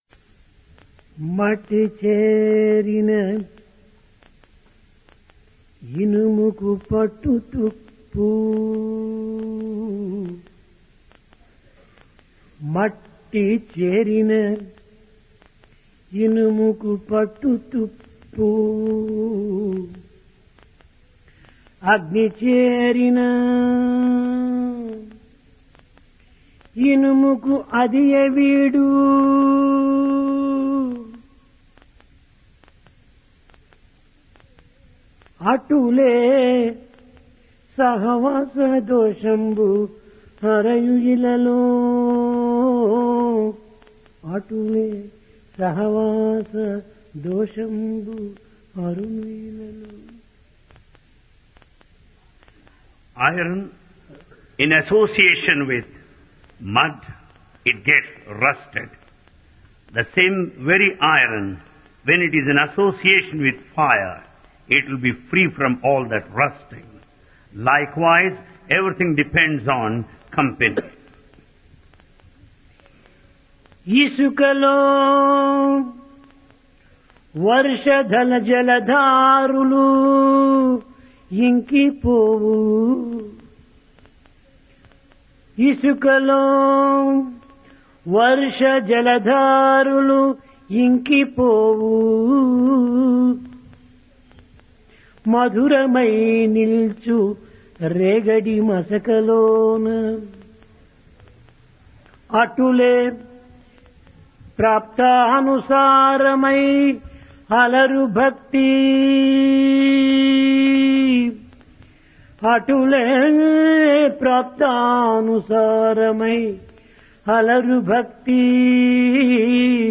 Discourse
Place Prasanthi Nilayam